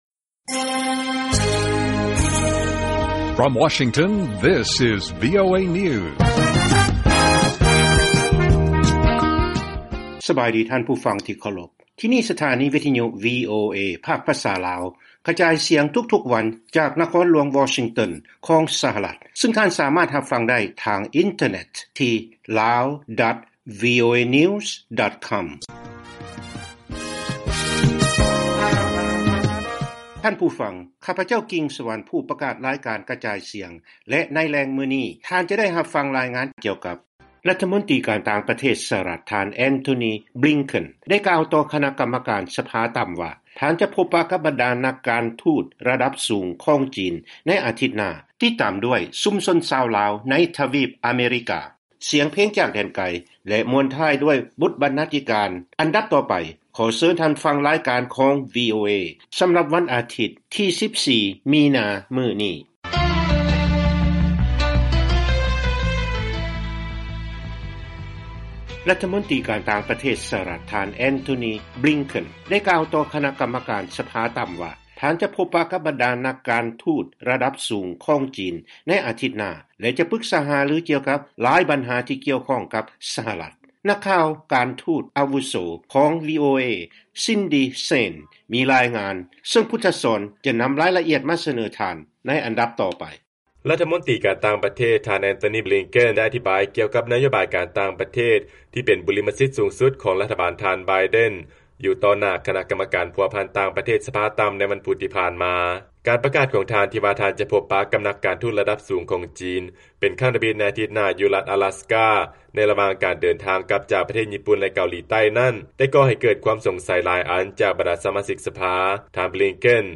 ວີໂອເອພາກພາສາລາວ ກະຈາຍສຽງທຸກໆວັນ. ຫົວຂໍ້ຂ່າວສໍາຄັນໃນມື້ນີ້ມີ